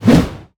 m_woosh_18.wav